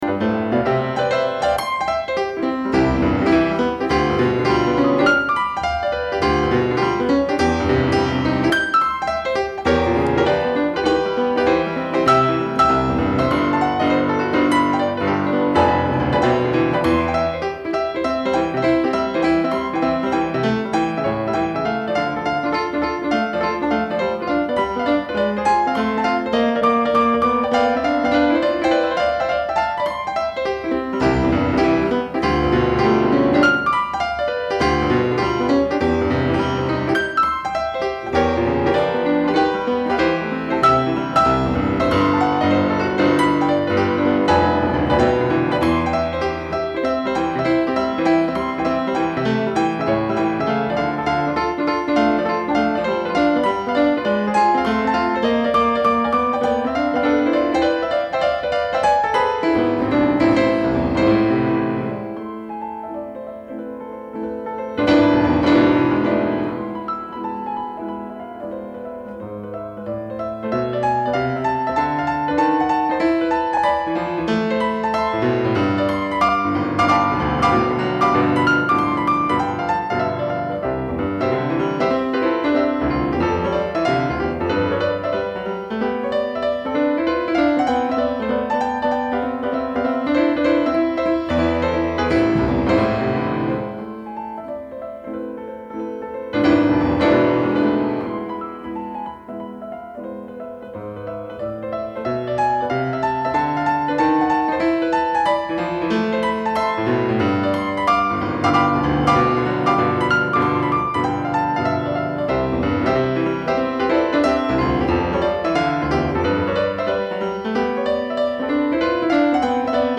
beethovenCette sonate n° 32 fut la dernière œuvre pour piano composée par Beethoven en 1822, il mourra quatre ans plus tard.
Le premier mouvement, d’abord lent et majestueux, entêtant pour ne pas dire envoûtant, se transforme en fugue presque baroque.
Quant à moi, parmi celles que je connais, l’interprétation que je place toujours et encore au premier plan est celle d’Yves Nat (enregistrement mono de 1954 !)